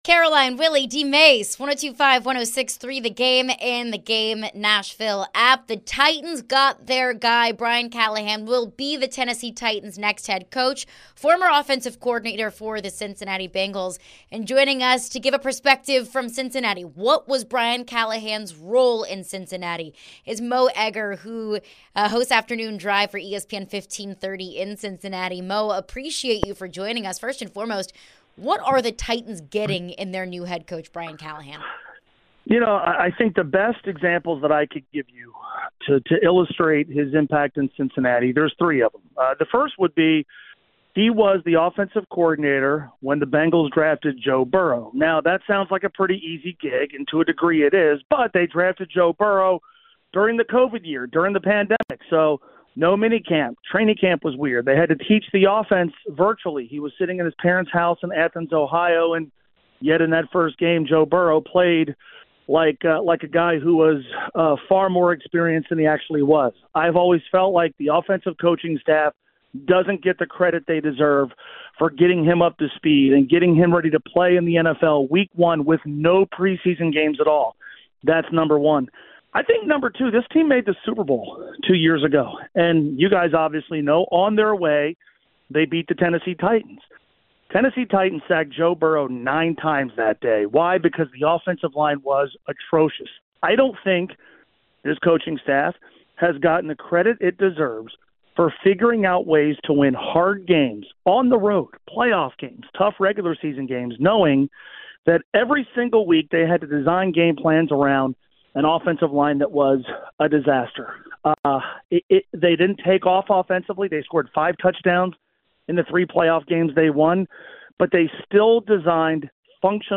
answer some calls and text about the new hire and other news surrounding the Titans.